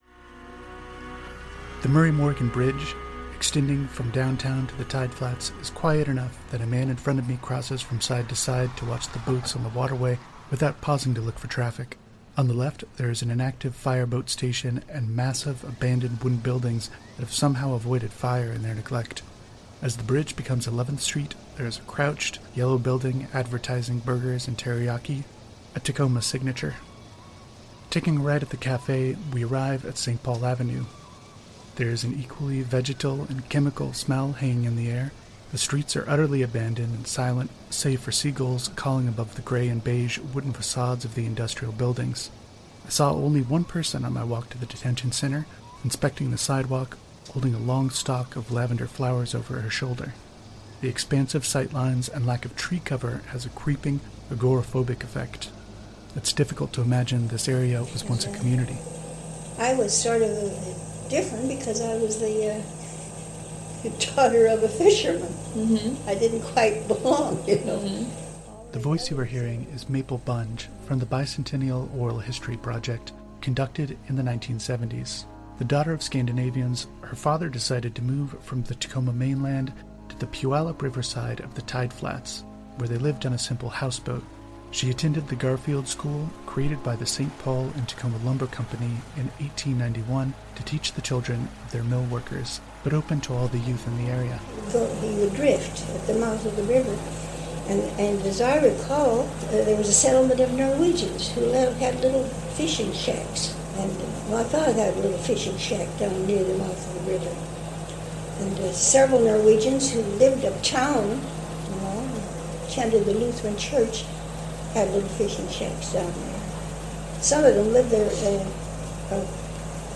At the time, I was living within walking distance of the detention center and one of the first things I did for the project was to take a handheld microphone and visit.
• Field recording (ambient sense of place)
• Background music (maybe used as reader cues that we are going backwards or forward in time)
• Narration
• Oral history recording
• Sound clips (kendo sparring, streetcars)